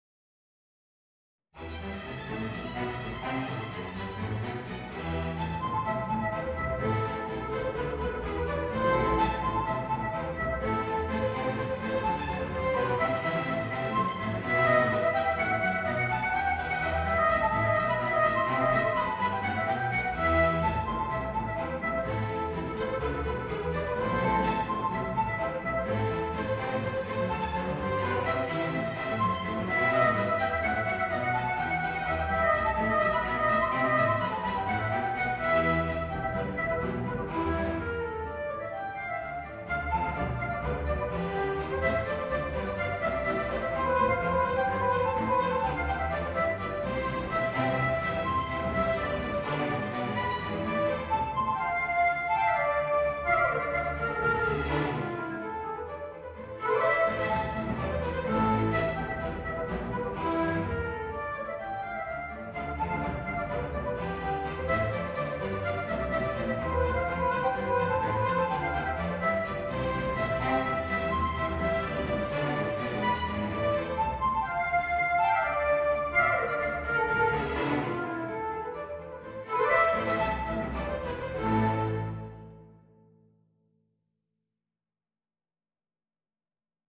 Voicing: Tenor Saxophone and String Orchestra